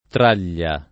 traglia [ tr # l’l’a ]